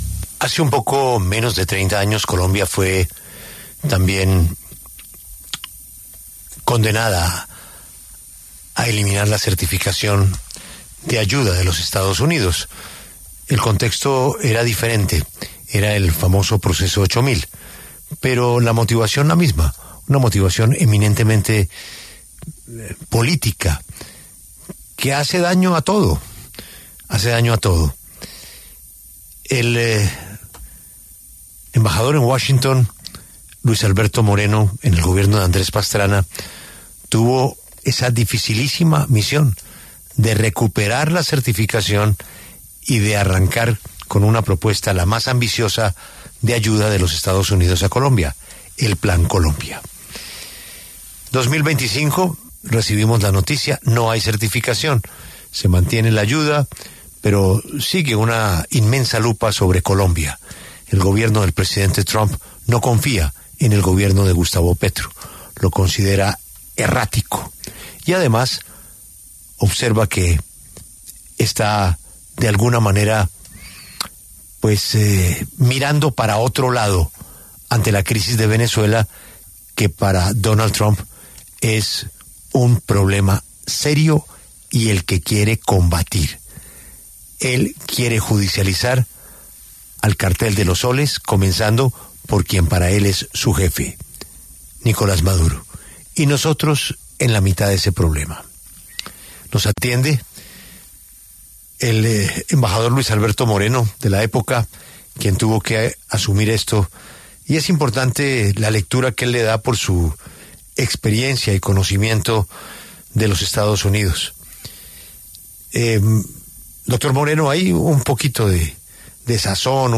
En diálogo con Julio Sánchez Cristo para La W, el exembajador Luis Alberto Moreno se pronunció acerca de la decisión de la administración del presidente de Estados Unidos, Donald Trump, de eliminar a Colombia de la lista de países que luchan contra el narcotráfico.